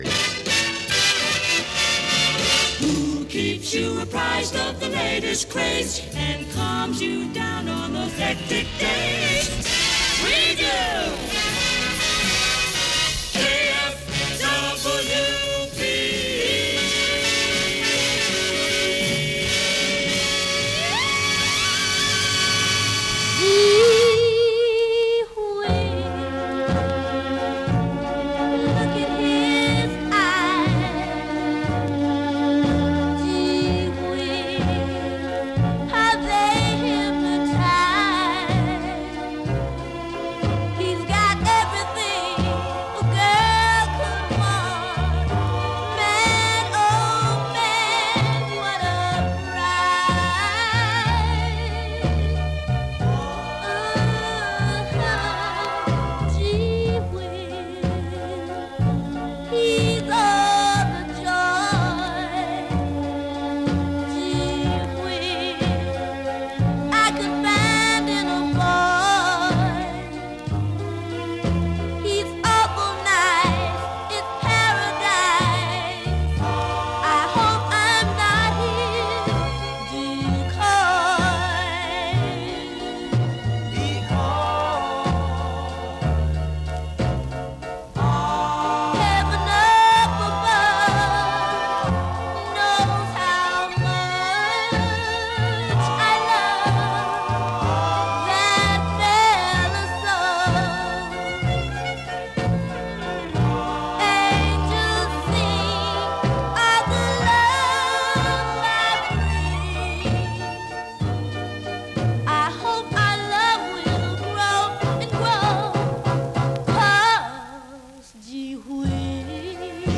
It was Andy Williams and The Marcels and bands you’ve never heard of. And the jocks were talking a lot, sometimes over the records – they wouldn’t shut up.